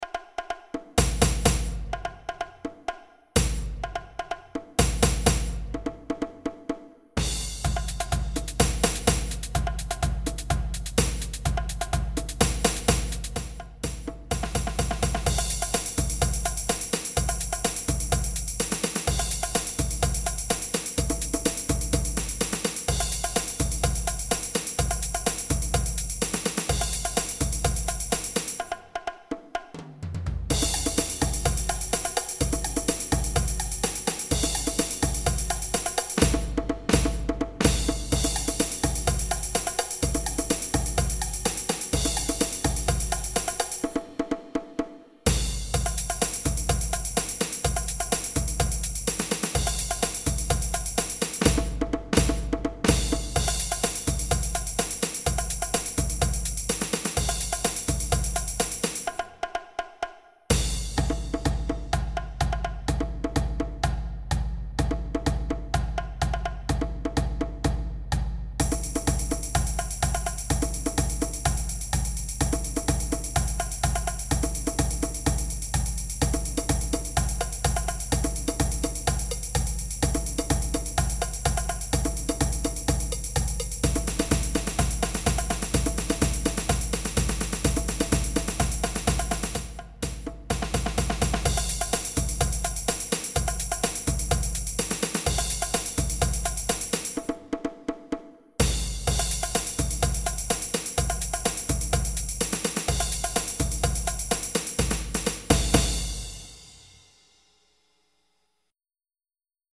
Junior Percussion